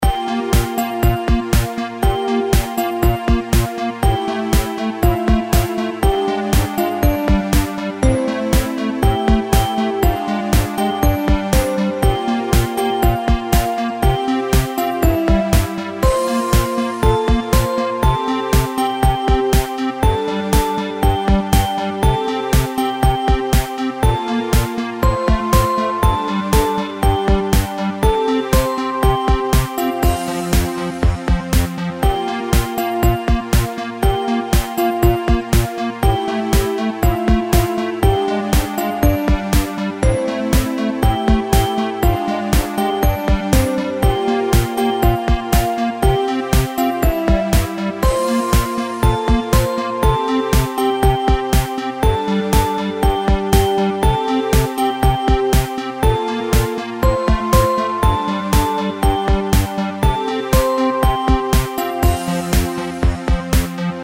インストゥルメンタルエレクトロニカ明るい
BGM